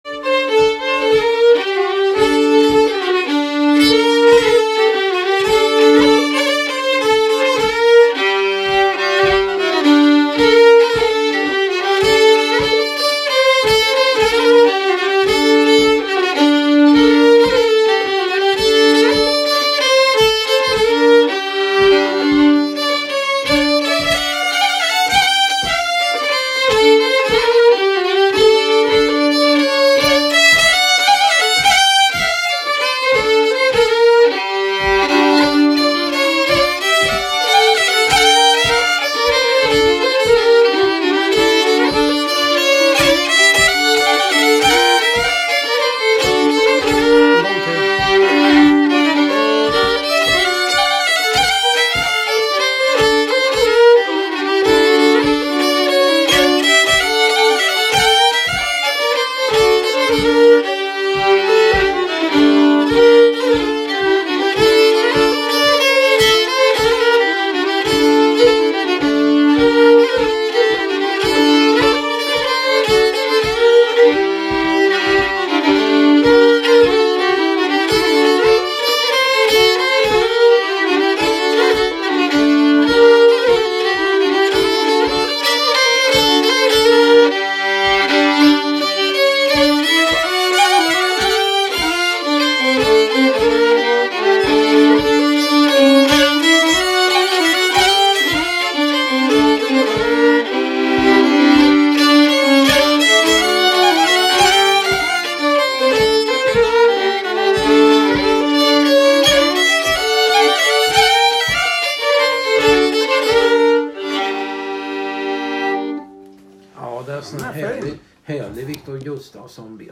Så var det dags att träffas igen och spela – tre gubbar från Karlskoga/Degerfors träffas och spelar lite beter ifrån Karlskoga och öst Värmland.
Nedan spelar vi alla tre en polska tillsammans och som vi gillar väldigt mycket.